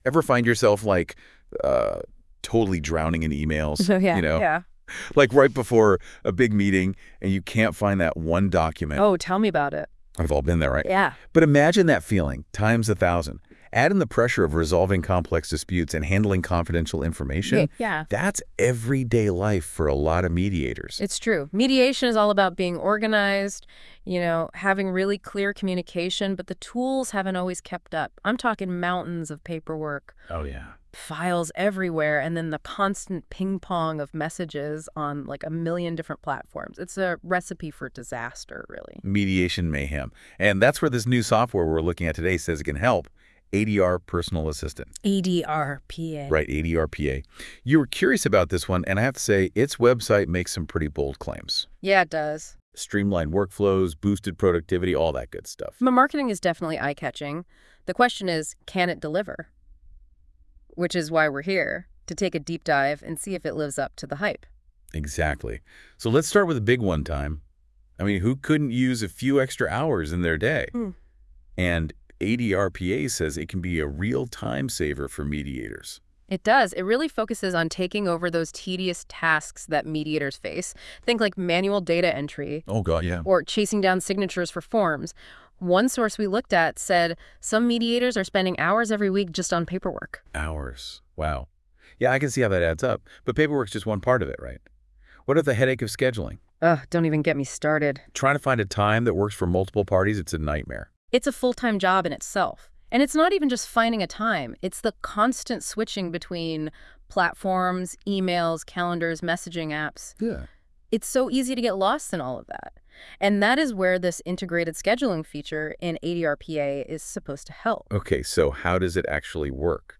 AI Generated Podcast
It sounds very natural and interactive but gets the message across incredibly well. We are proud of the result and believe it showcases our platform for all mediators.